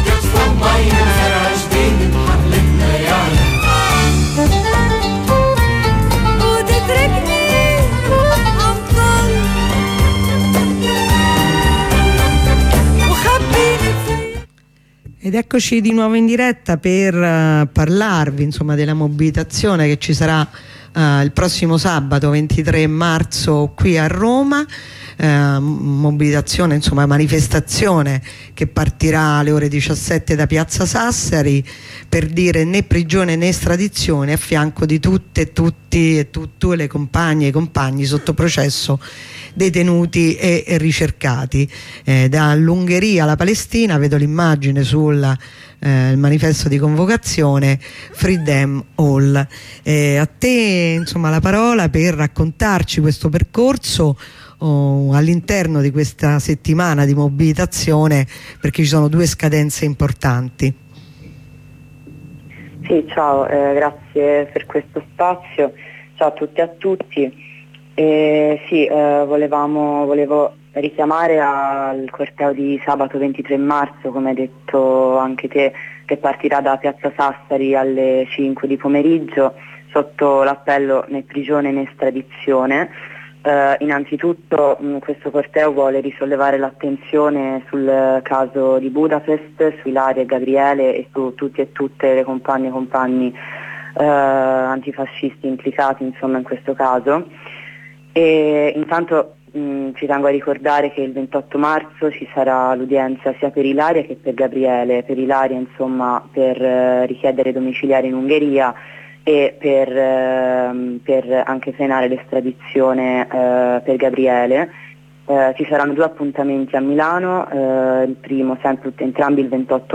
Intervento dal presidio